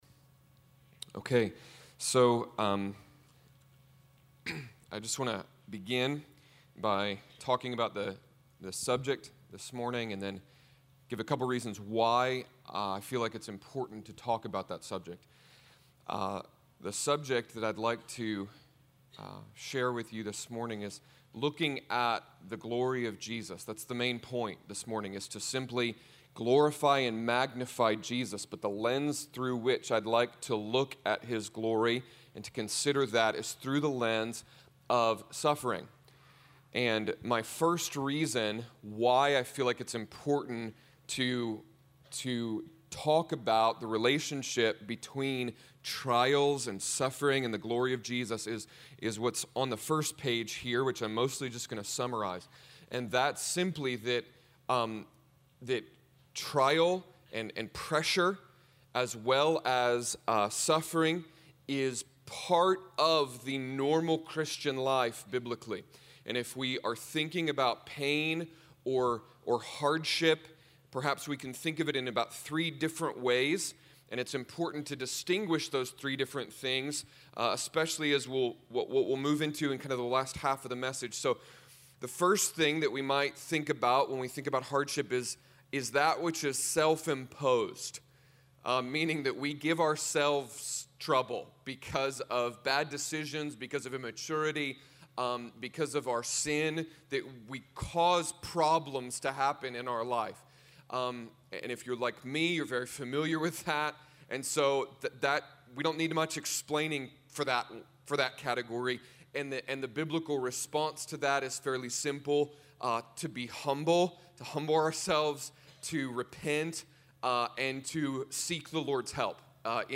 Spoken on November 7th, 2010 at Forerunner Christian Fellowship.